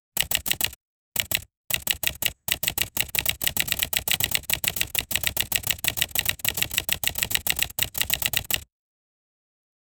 Fast mechanical typewriter typing sound with rhythmic key clicks, no background music, clear and isolated audio for AI text generation effect.
fast-mechanical-typewrite-qic54lx7.wav